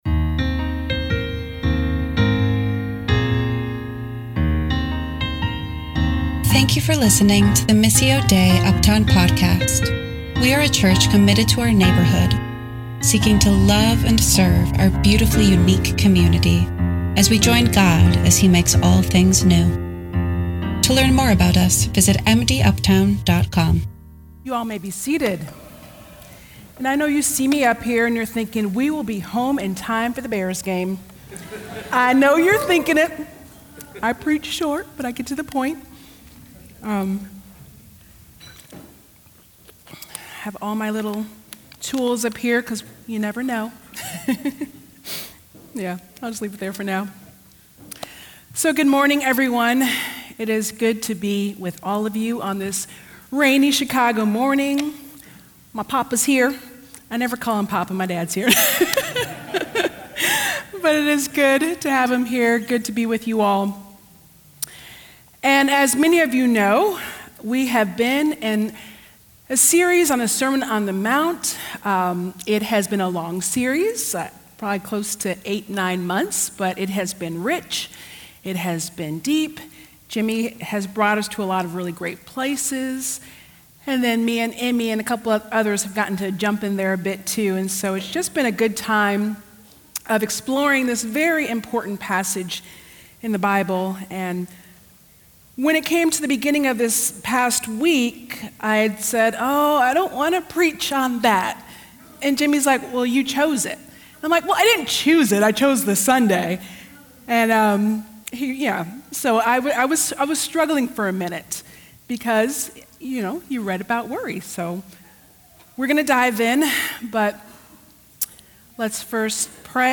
The Sermon